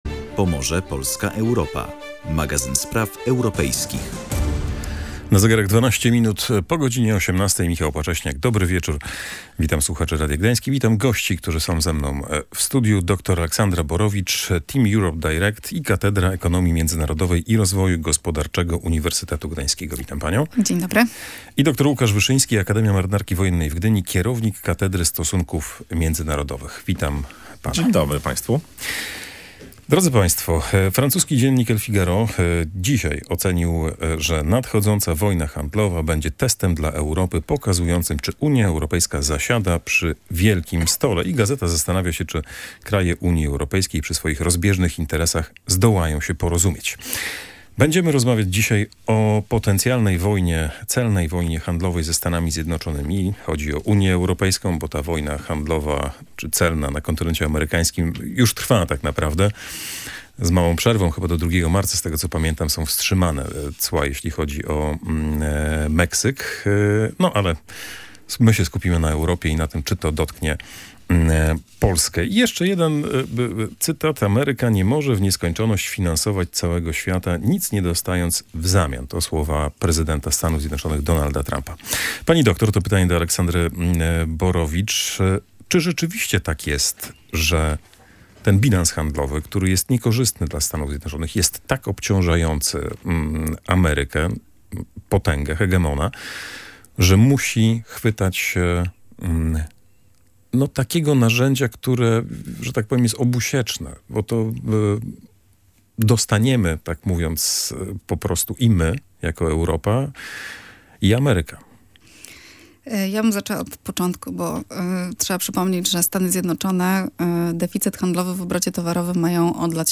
Na powyższe i wiele innych pytań odpowiedzieli goście audycji „Pomorze, Polska, Europa”